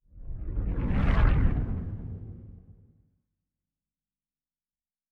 pgs/Assets/Audio/Sci-Fi Sounds/Movement/Distant Ship Pass By 4_3.wav at master
Distant Ship Pass By 4_3.wav